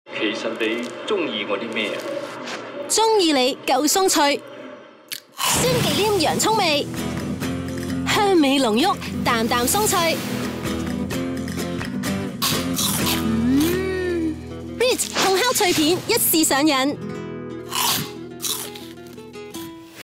Never any Artificial Voices used, unlike other sites.
Yng Adult (18-29) | Adult (30-50)